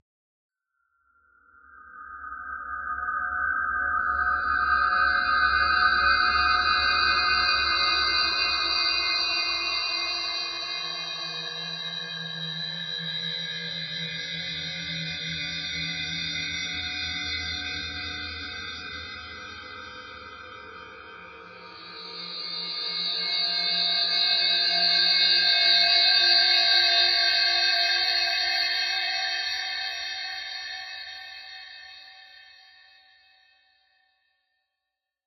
Threads of noise, tape loops, and samples.
In the past couple of days I have listened to the sound of my breathing through the medium of a heavy cold, and while I think it’s kind of cool, it might not be to everyone’s taste (particularly if you are not into ASMR), so here it is heavily processed.